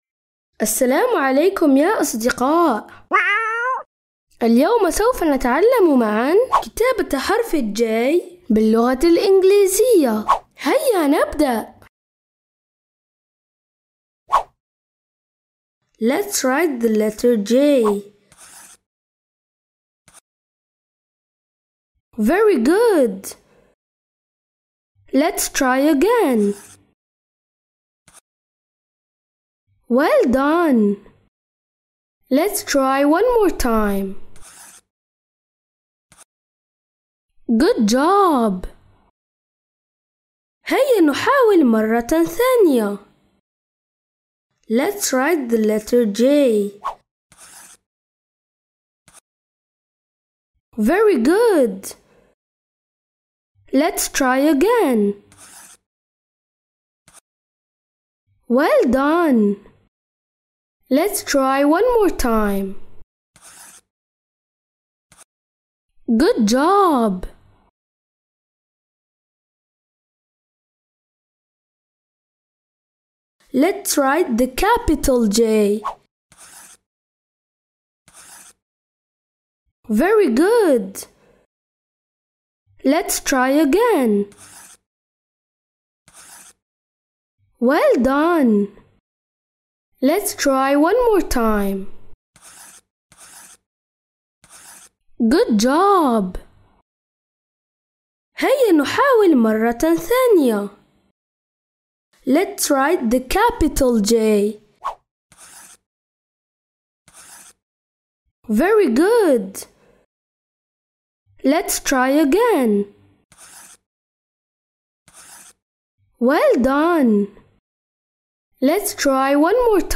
تعليم اللغة الانجليزية -حرفJ
برنامج تعليم اللغة الانجليزية تستمعون إليه عبر إذاعة صغارنا كل احد الساعة 1:00 مساء